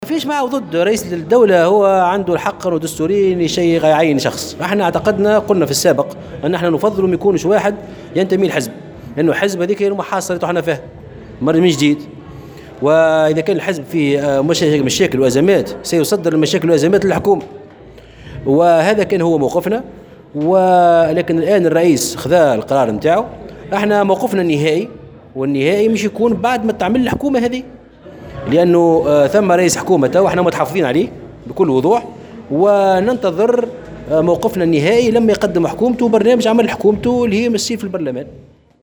صرح الامين العام لحزب مشروع تونس محسن مرزوق، محسن مرزوق، لمراسلة الجوهرة أف أم، على هامش اجتماعه بأعضاء كتلة الحزب في مجلس النواب وإطارات الحزب، مساء اليوم الثلاثاء في بالحمامات، بأن "المشروع" كان قد أعلن سابقا أنه يفضل أن يكون رئيس الحكومة غير منتميا حزبيا.